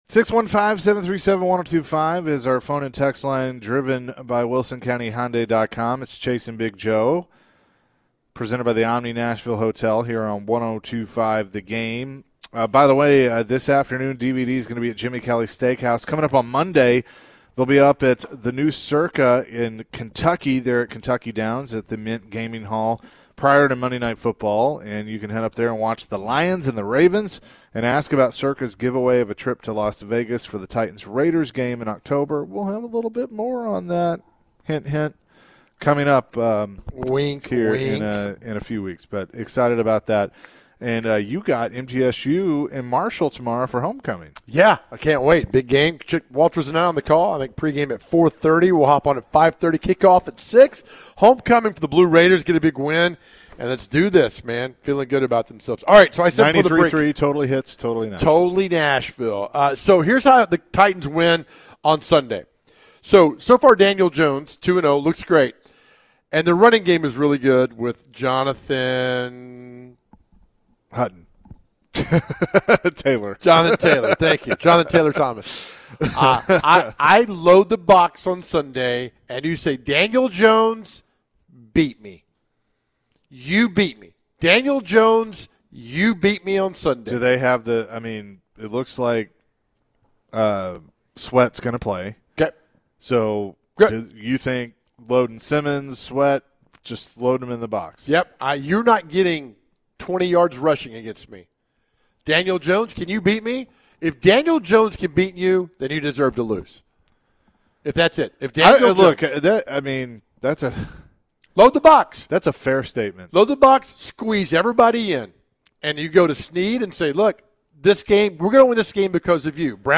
The guys chatted with Forever Titans WR Chris Sanders about the Titans and the upcoming game.